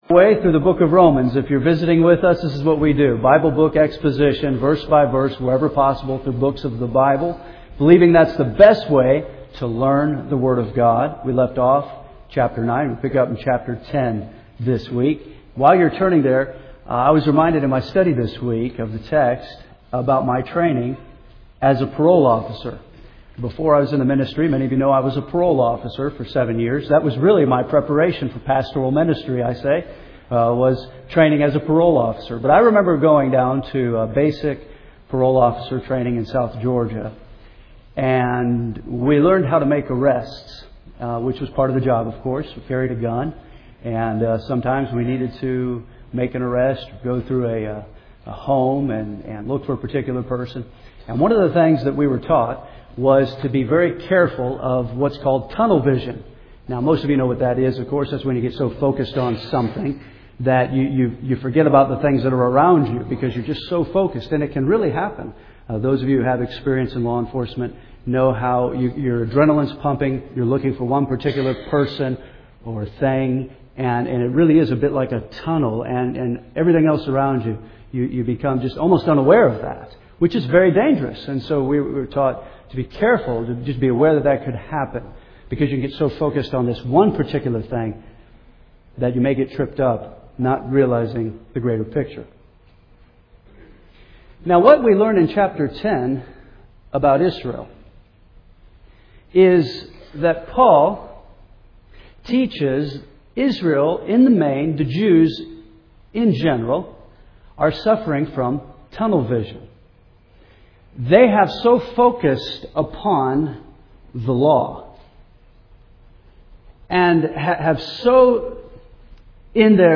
First Baptist Henderson, KY